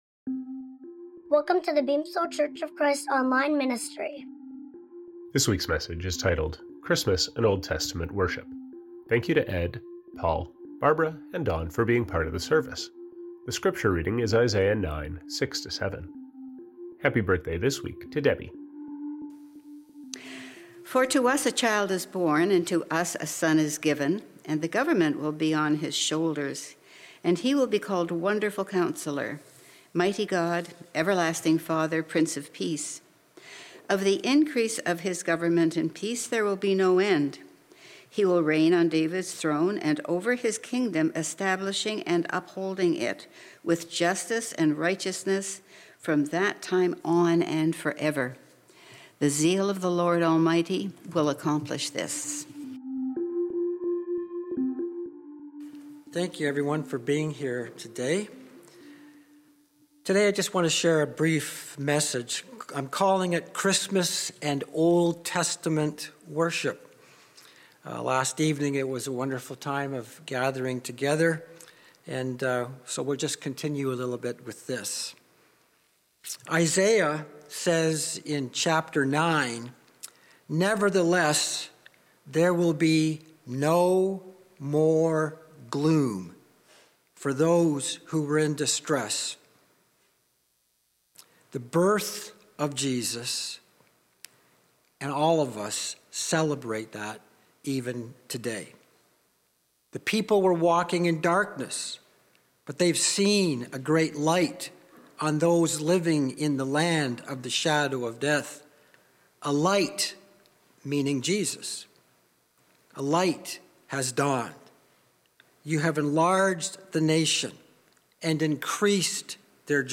The weekly sermon from the Beamsville Church of Christ